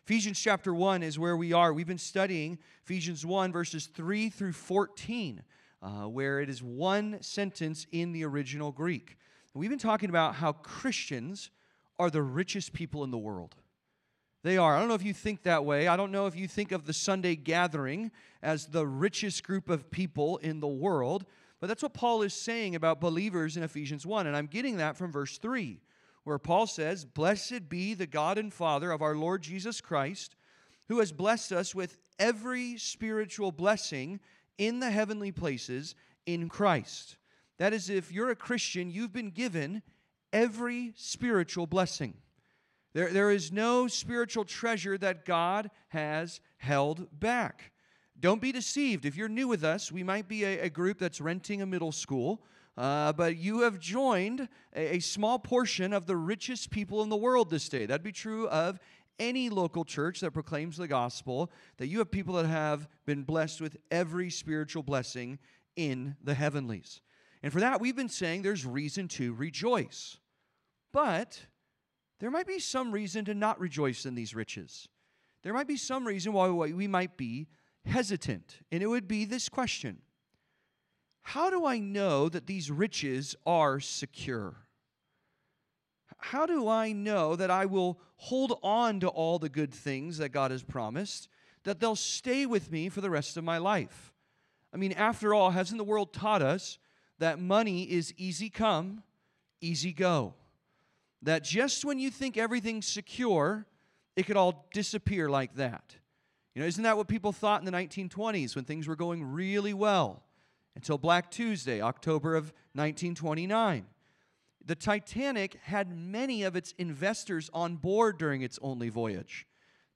Secured for His Praise (Sermon) - Compass Bible Church Long Beach